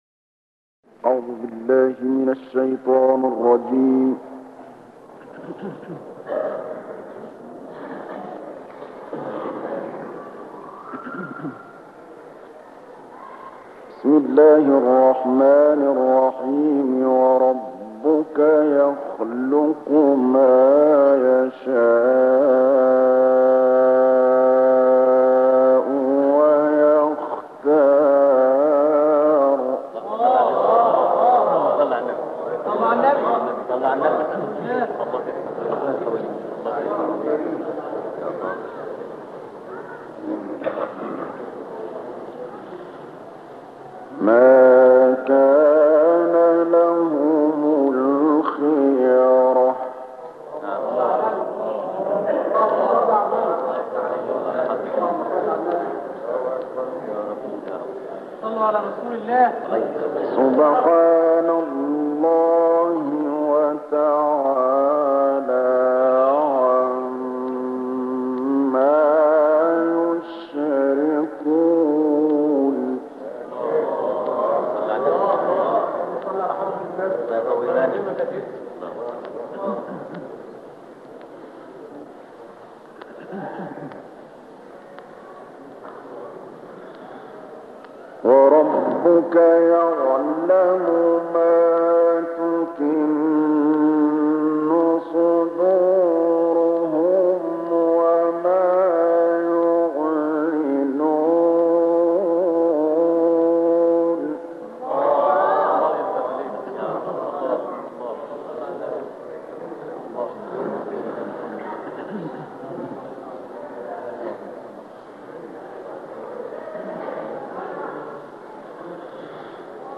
دانلود قرائت سوره قصص - استاد محمود طبلاوی